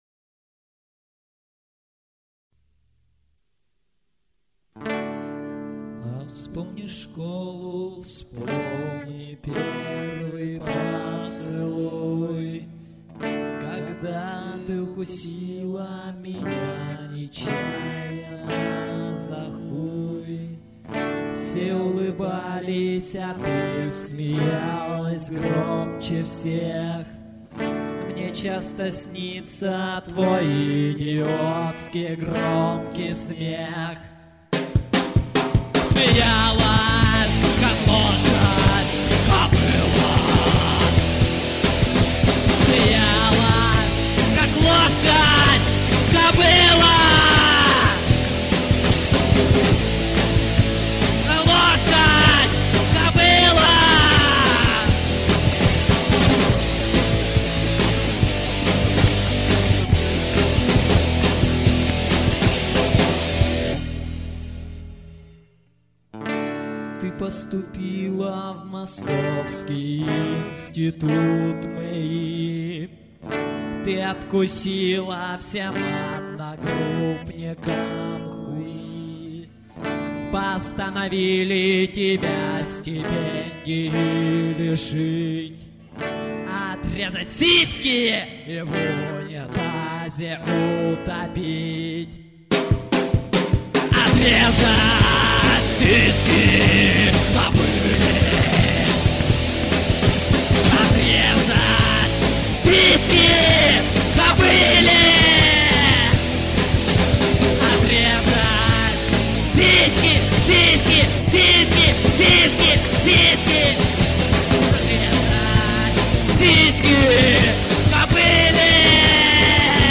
1-6: Записано на студии "Рай" весной 2004г.